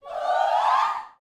SHOUTS20.wav